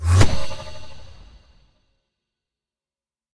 Minecraft Version Minecraft Version 25w18a Latest Release | Latest Snapshot 25w18a / assets / minecraft / sounds / mob / illusion_illager / mirror_move2.ogg Compare With Compare With Latest Release | Latest Snapshot